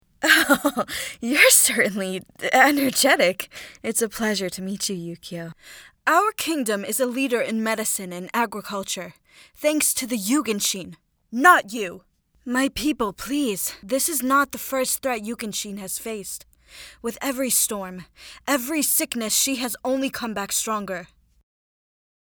Young royal female